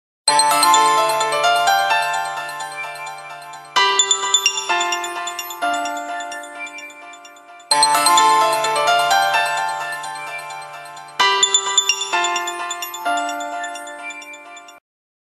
HTC - Innovation Ringtone .mp3